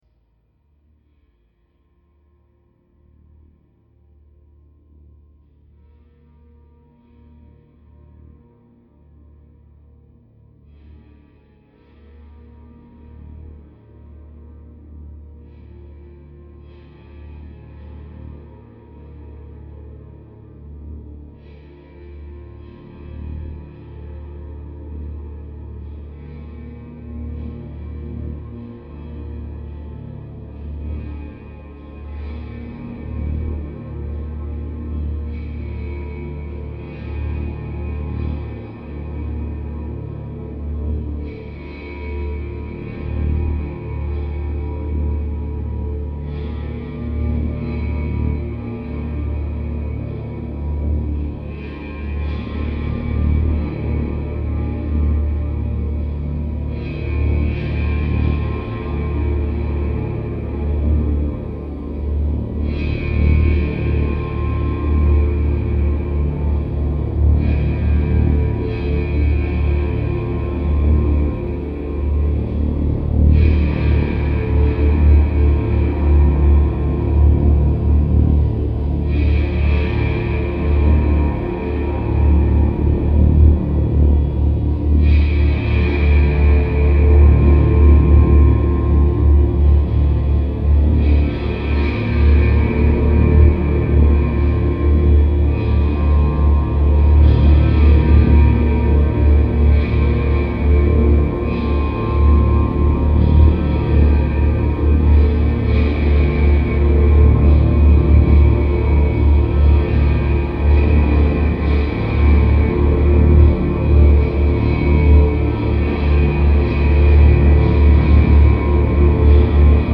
darkest, bleakest industrial soundscapes